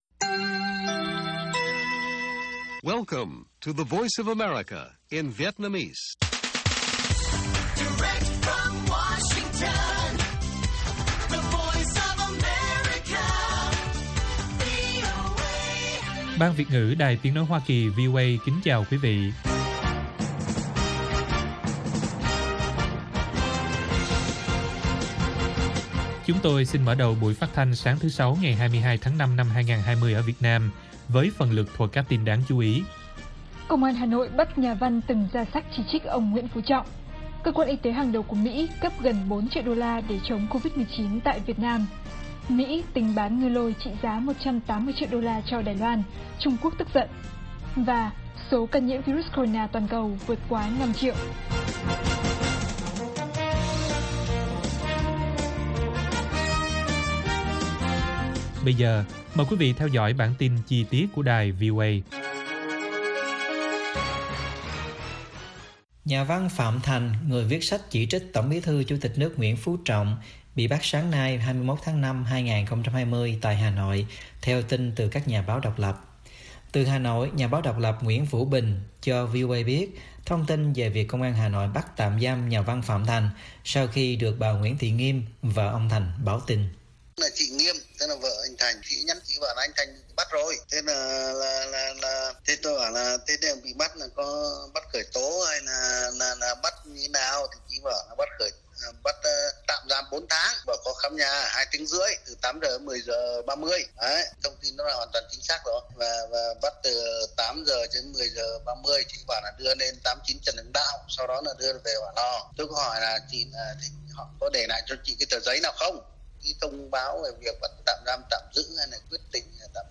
Bản tin VOA ngày 22/5/2020